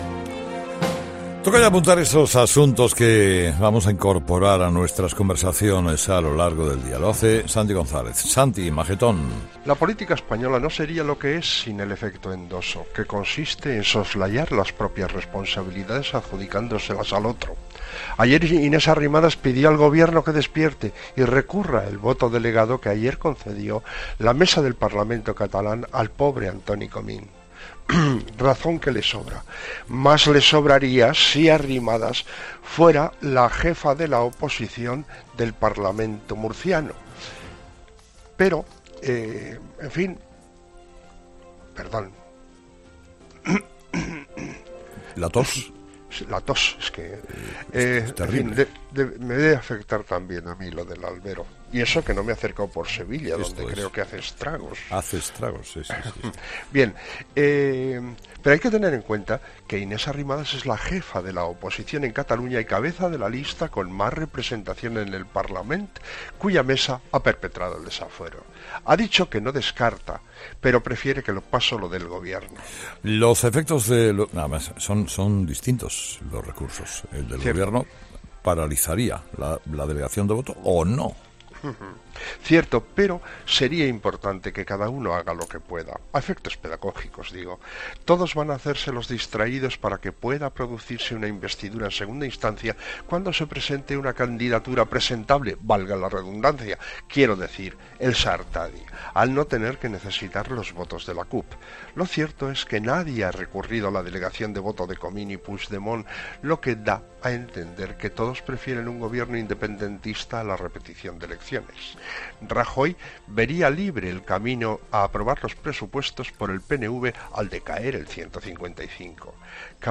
El comentario de Santiago González